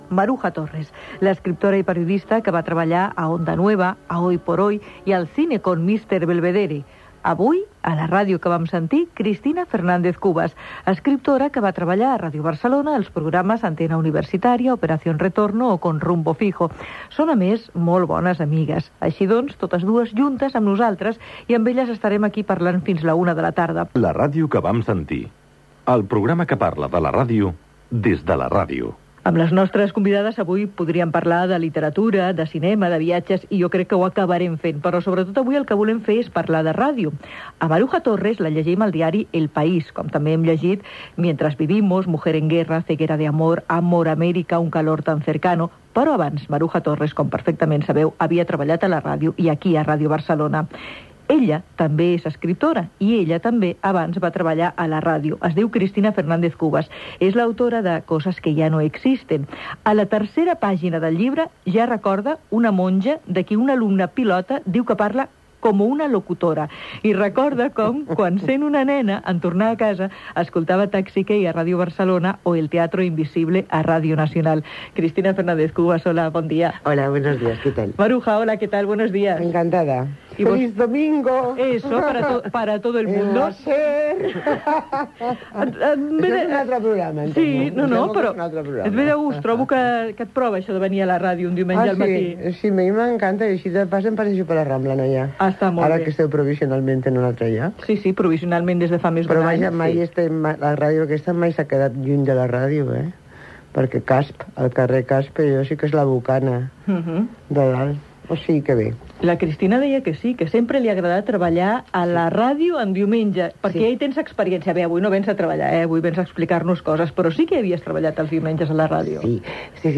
Conversa amb Maruja Torres i Cristina Fernández Cubas sobre el seu pas per la ràdio i el consultori d'Elena Francis